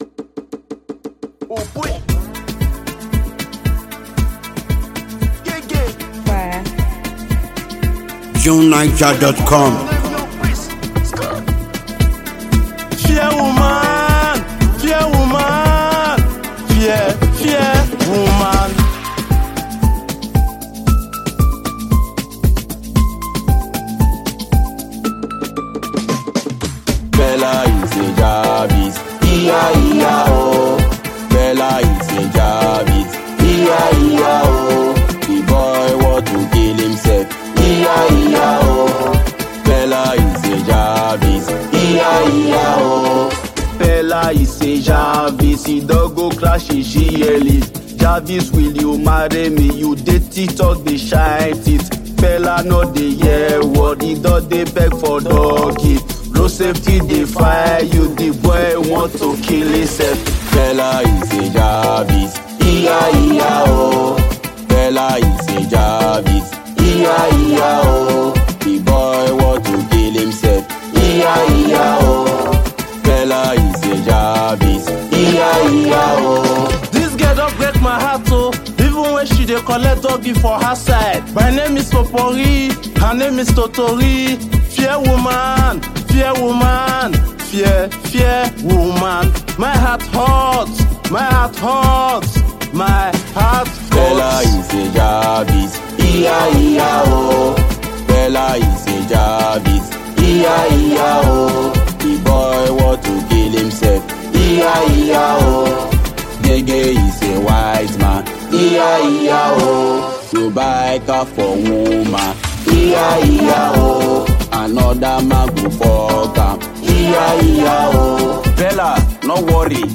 hilarious and seductive album
he worked with two amazing vocalists
to create this captivating and exciting tune.